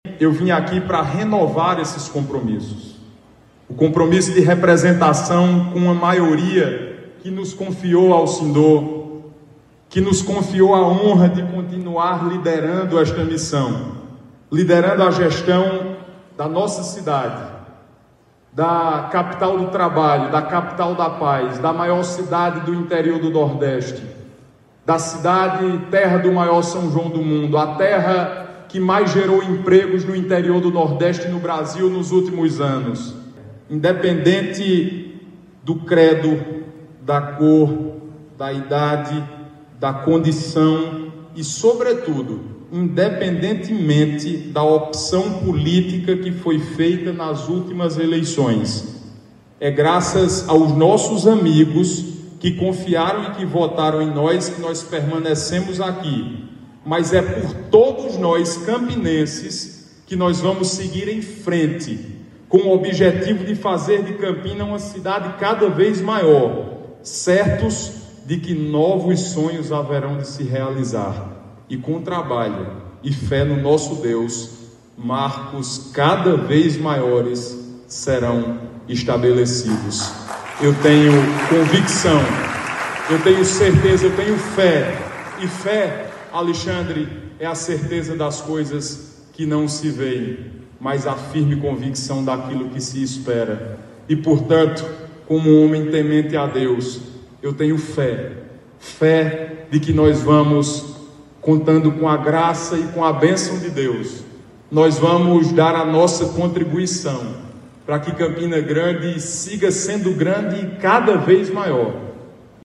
Em discurso de posse, Bruno renova compromisso pela unidade da gestão pelo bem de Campina Grande
Os comentários de Bruno foram registrados pelo programa Correio Debate, da 98 FM, de João Pessoa, nesta quinta-feira (02/01).